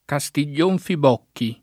Castiglion Fibocchi [kaStil’l’1n fib1kki o
kaStil’l’1n fib0kki] top. (Tosc.) — con -o- di Fibocchi aperto la pn. preval. in Tosc., prob. originaria (Fibocchi «figlio di Bocco»); con -o- chiuso la pn. loc. (e della Tosc. aret.), prob. dovuta all’attraz. di bocca: doppia pronunzia analoga a quella del cogn. Bocchi